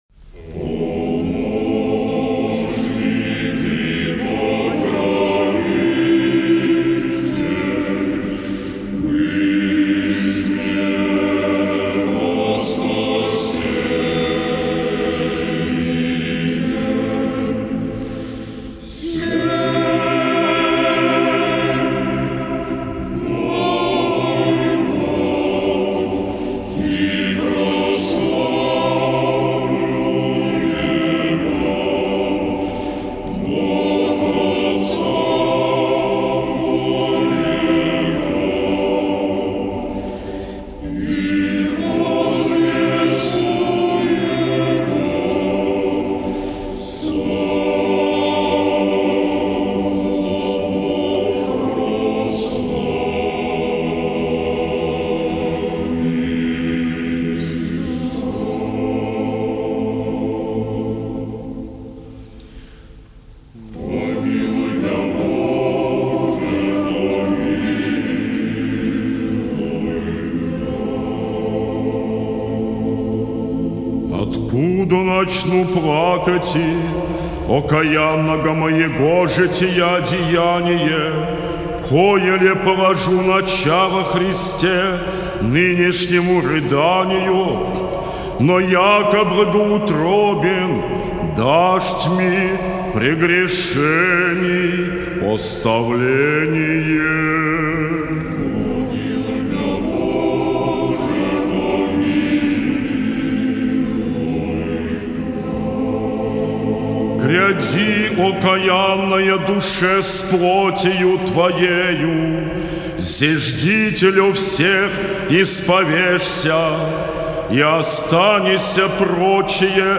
Величественная простота музыки
Запись сделана Великим Постом 2005 года.